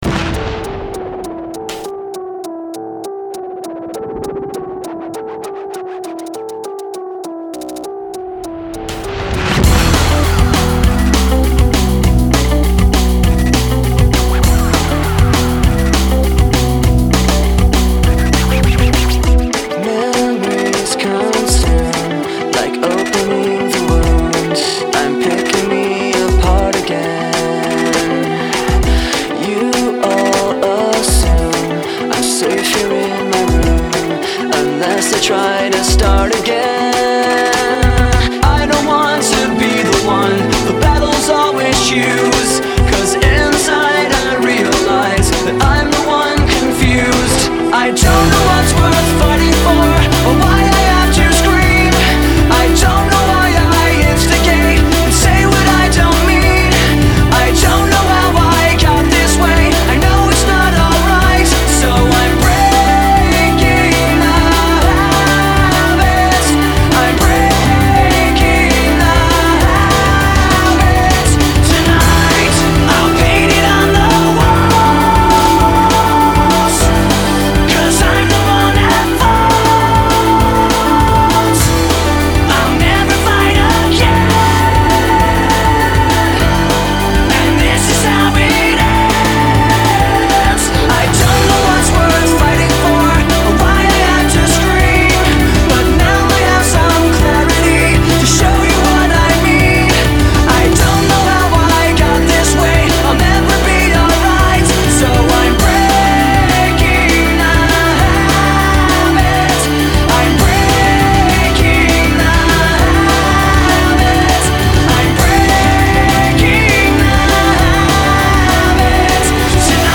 BPM200-200
Audio QualityMusic Cut